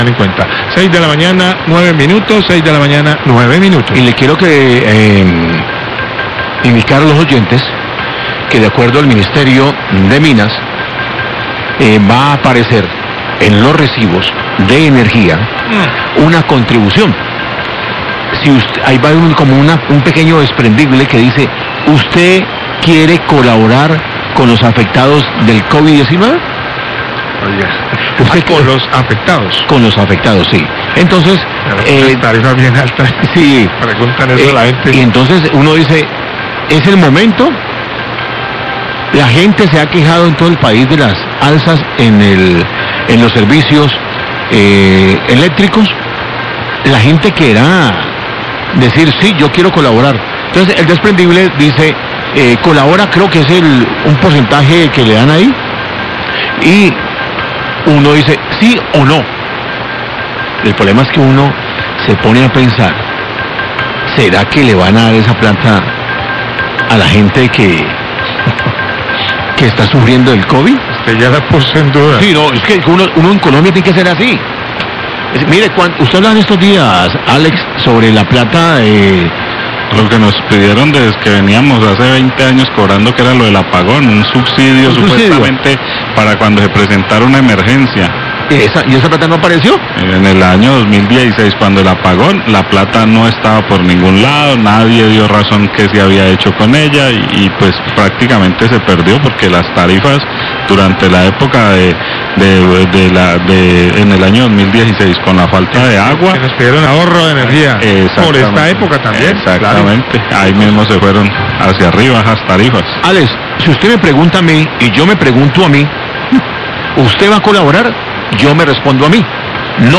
Radio
Periodistas de La Cariñosa opinian que es una mala idea después de las quejas por los incrementos en el valor de los recibos, cómo confiar en que esa plata sí será invertida.